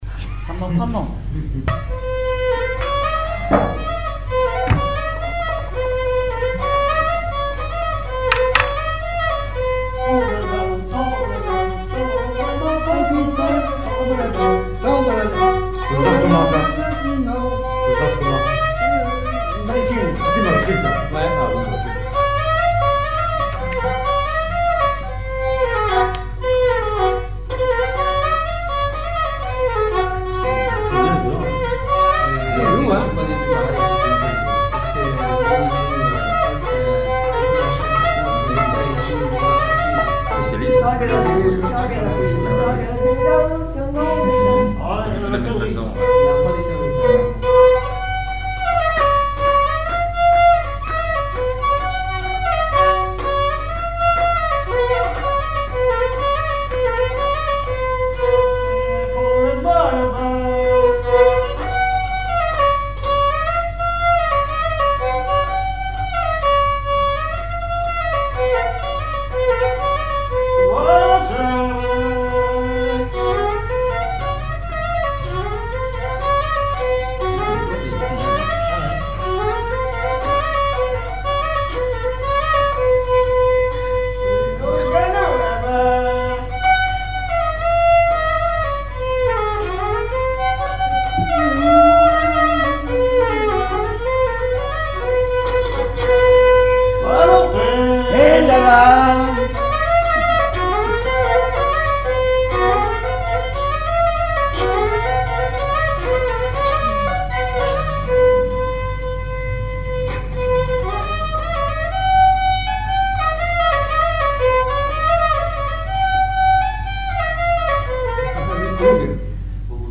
danse : branle : avant-deux
chansons populaires et instrumentaux
Pièce musicale inédite